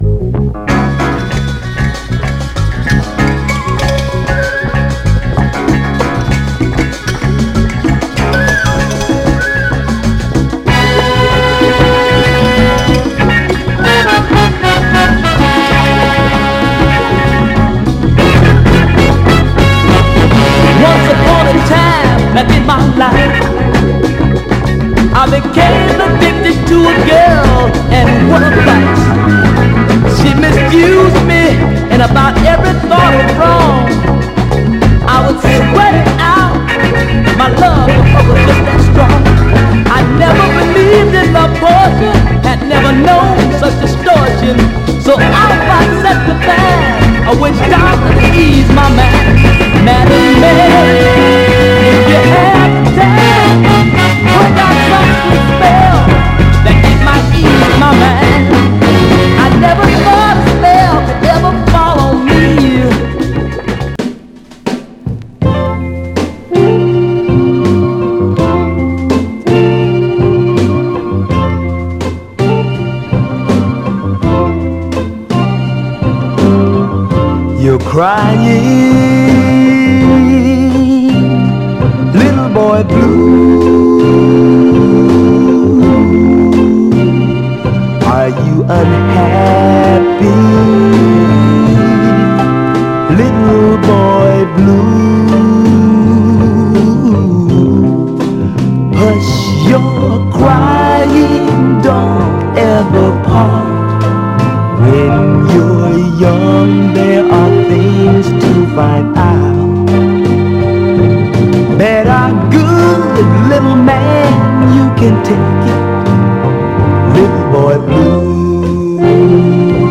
盤はA面にいくつか薄い小キズ箇所ありますが、グロスがありプレイ良好です。
※試聴音源は実際にお送りする商品から録音したものです※